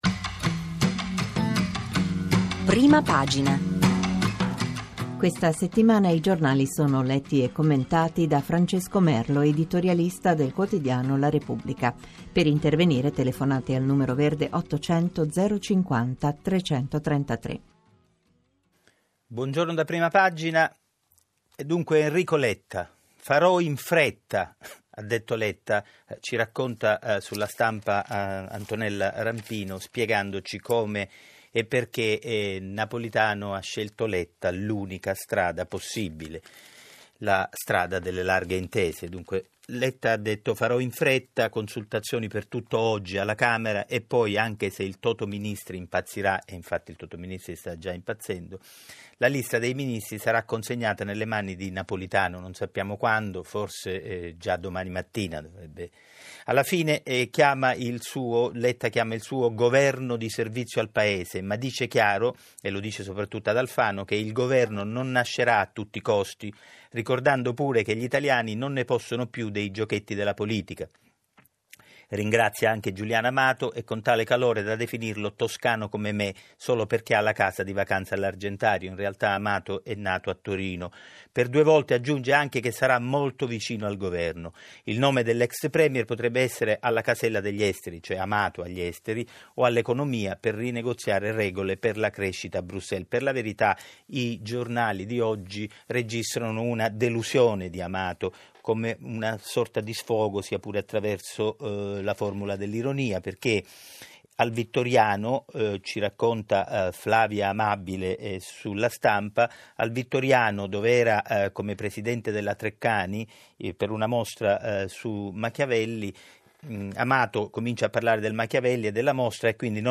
Rassegna stampa di Rai/Radio3 Prima Pagina del 25 aprile 2013: